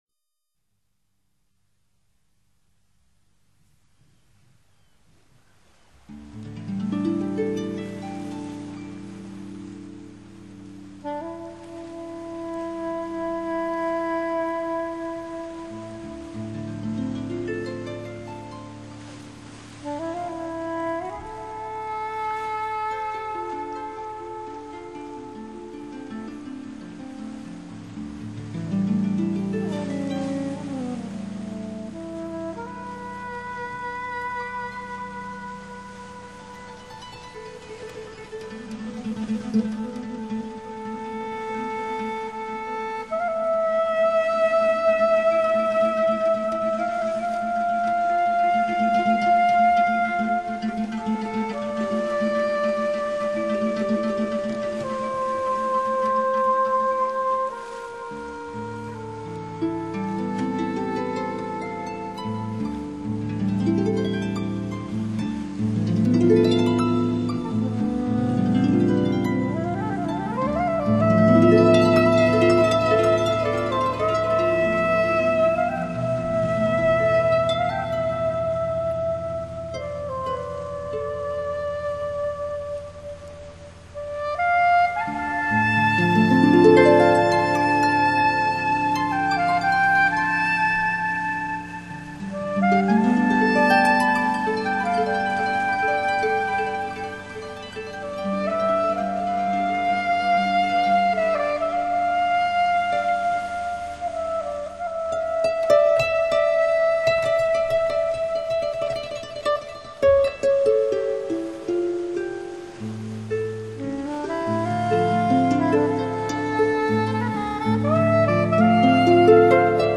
竖琴音乐近百年来,最彻底的超现代主义表现！